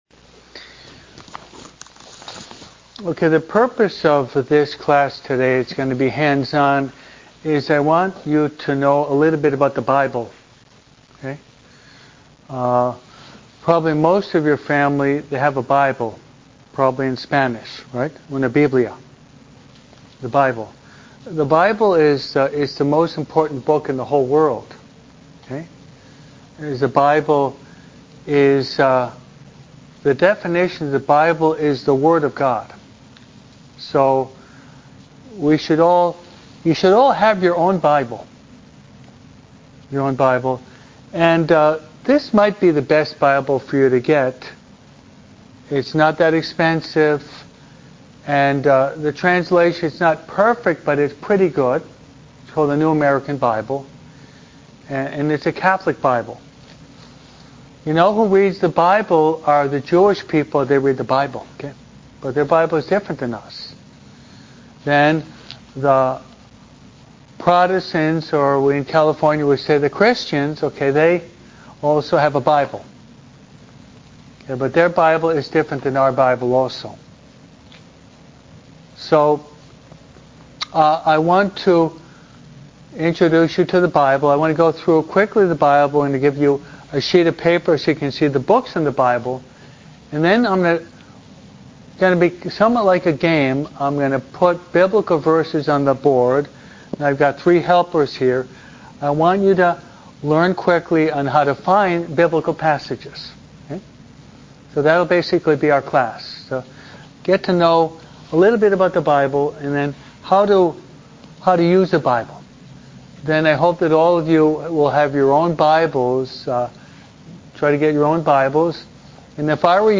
51100E-USING-THE-BIBLE-CONFIRMATION-CLASS.mp3